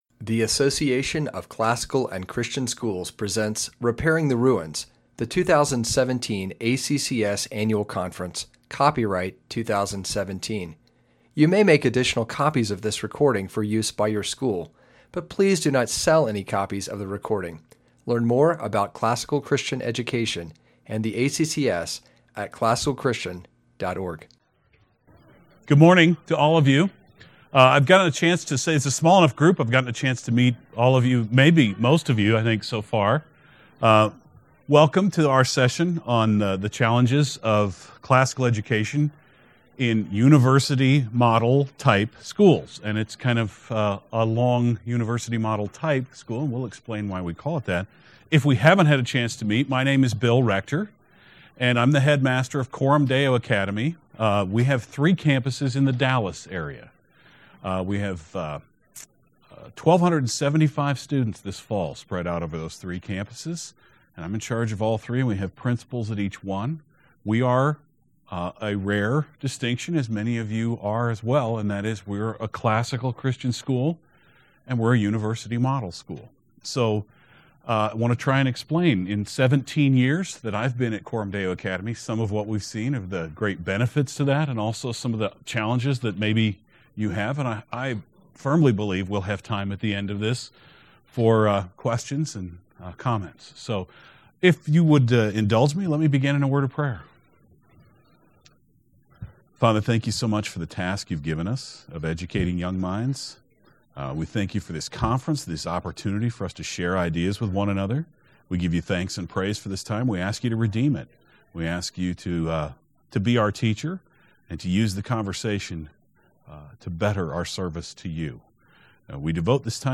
2017 Workshop Talk | 0:58:48 | All Grade Levels, Leadership & Strategic
Speaker Additional Materials The Association of Classical & Christian Schools presents Repairing the Ruins, the ACCS annual conference, copyright ACCS.